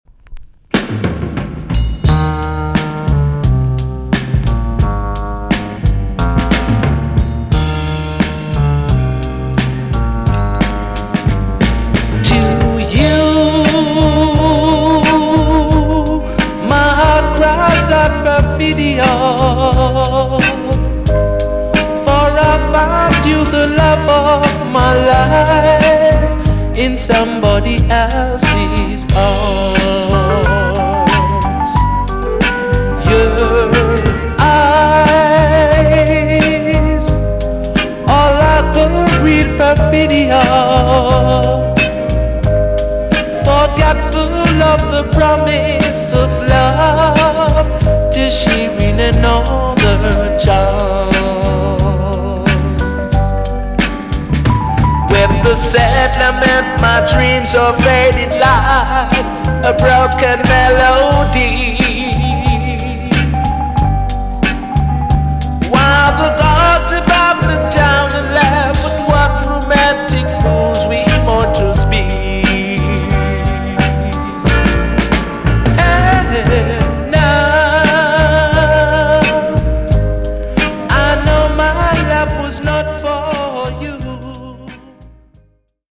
TOP >LP >VINTAGE , OLDIES , REGGAE
B.SIDE EX- 音はキレイです。